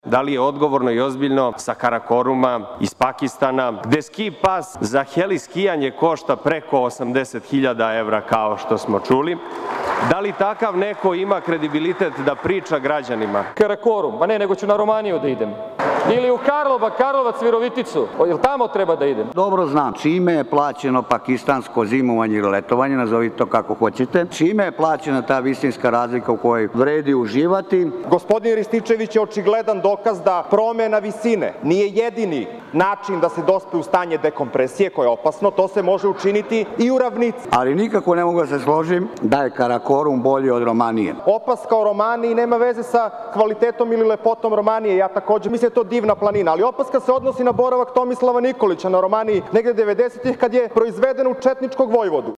Poslušajte deo u kojem predlagač zakona ministar Nikola Selaković otvara temu, koja već mesecima puni tabloide, a tiče se planinarenja lidera LDP-a u Pakistanu. Odgovara mu Čedomir Jovanović, naon čega se u raspravu uključuju i Marjan Rističević iz poslaničkog kluba Naprednjaka i Bojan Đurić iz LDP-a.
Rasprava poslanika